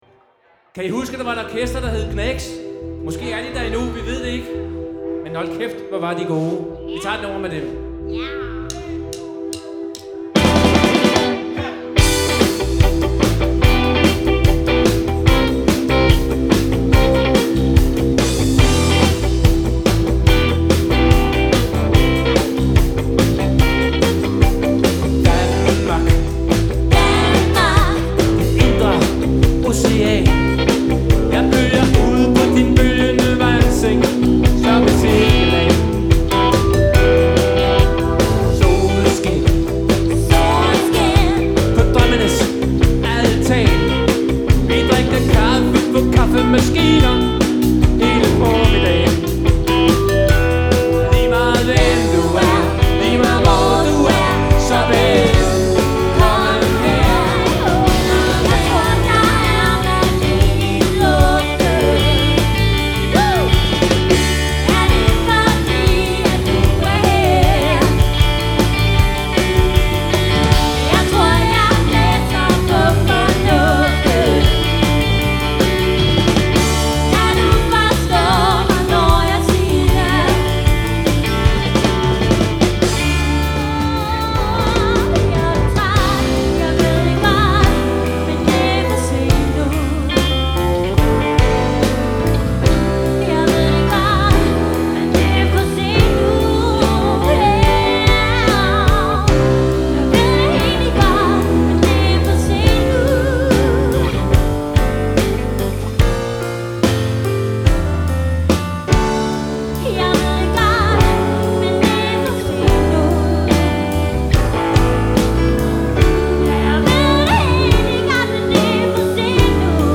Live band m/ indlevelse og top underholdning.
• Coverband
• 1. Maj i Fælledparken. (Dansk Medley live )